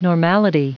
Prononciation du mot normality en anglais (fichier audio)